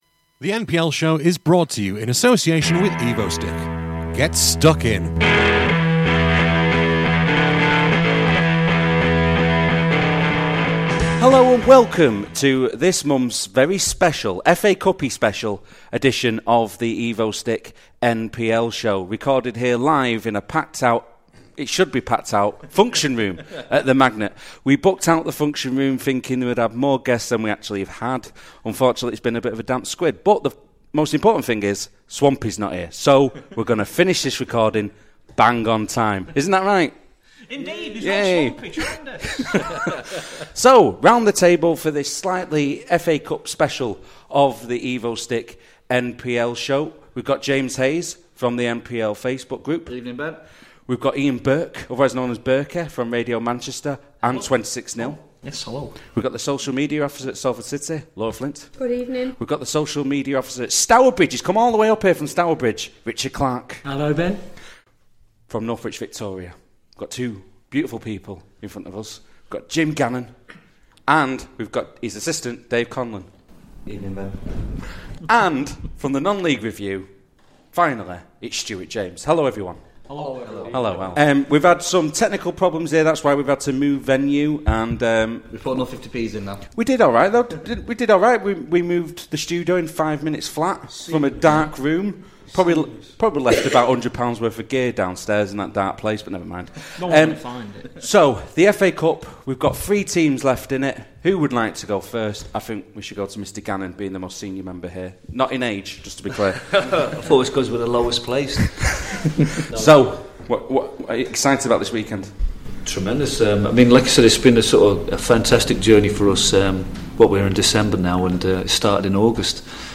This programme was recorded at the Magnet in Stockport on Wednesday 2nd December 2015.